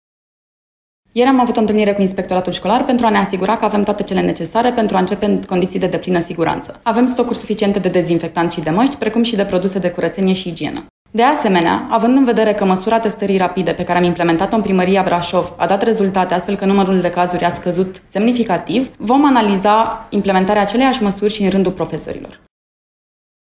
Viceprimarul municipiului Brașov, Flavia Boghiu: